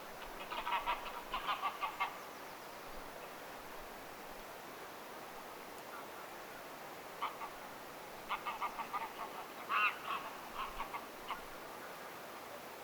merihanhikoiras
merihanhikoiraan_arsyttavaa_aantelya.mp3